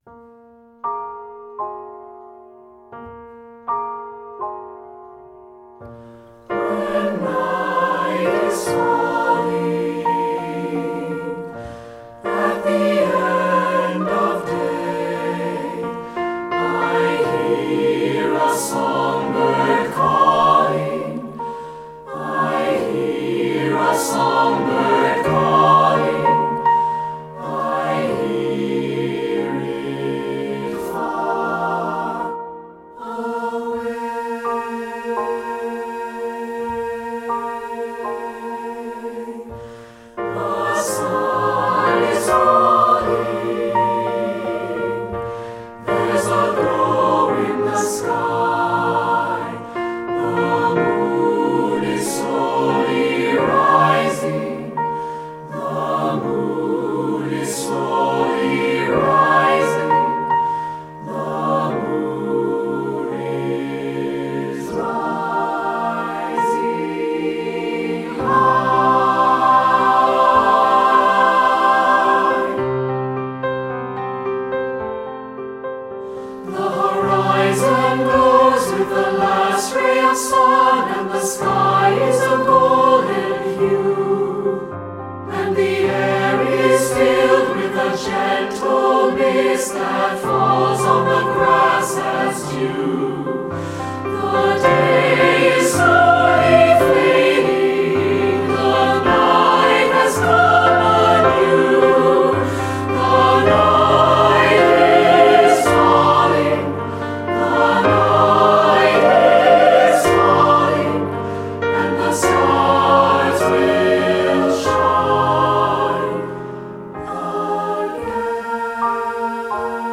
Voicing: SSATB